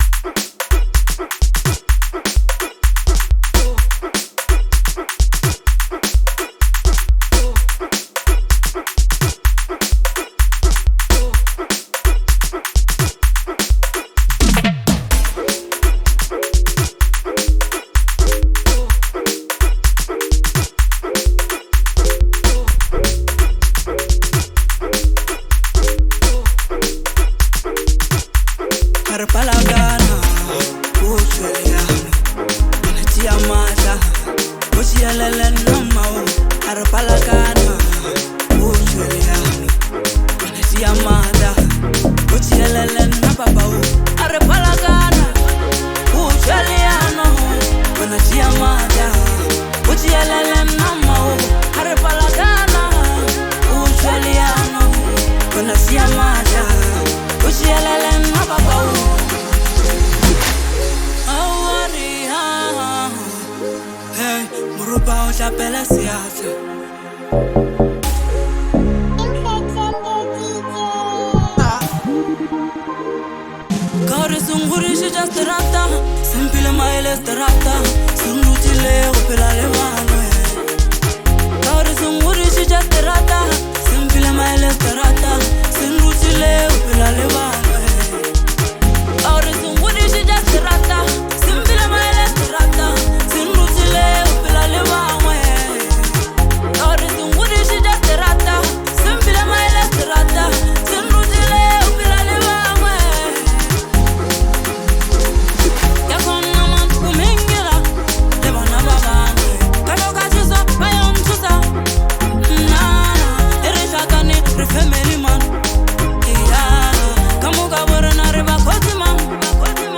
deeply emotional